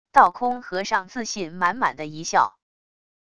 道空和尚自信满满的一笑wav音频